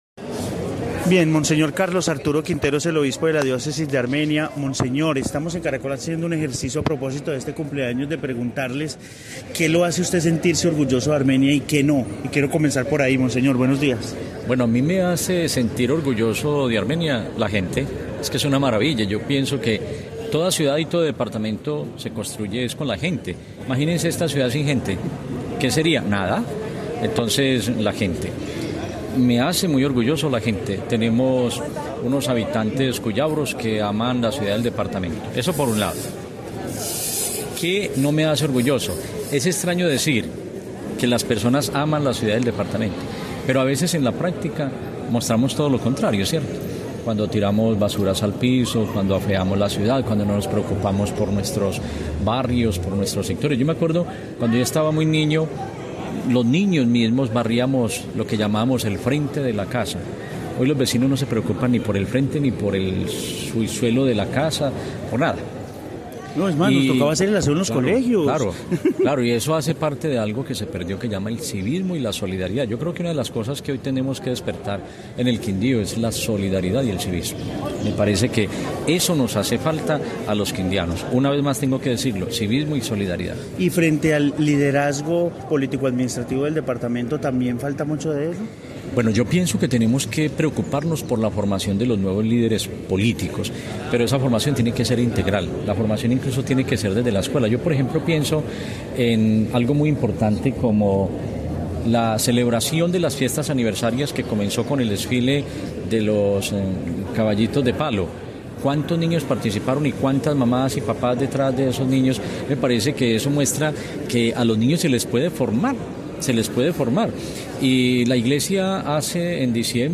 Monseñor Carlos Arturo Quintero, Obispo de Armenia